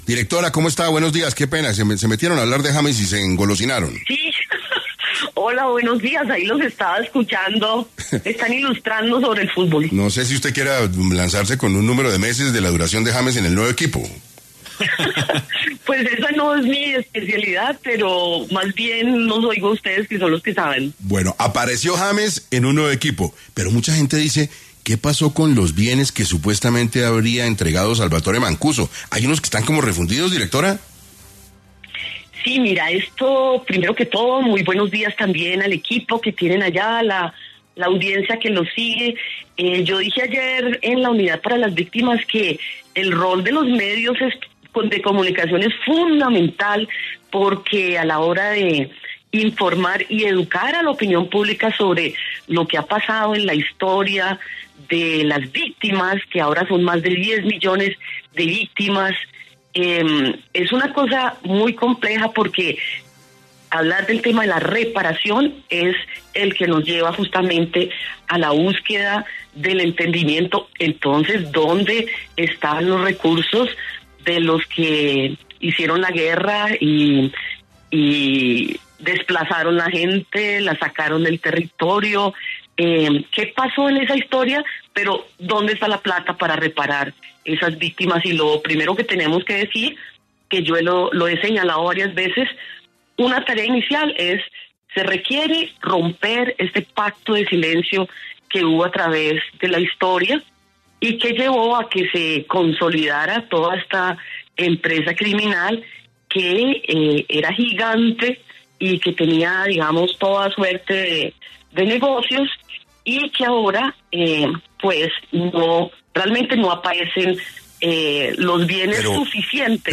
En 6AM de Caracol Radio estuvo Lilia Solano, directora de la Unidad de Víctimas, para hablar sobre qué hay detrás de la desaparición de más de 100 bienes que habría entregado Salvatore Mancuso al fondo de reparación de víctimas.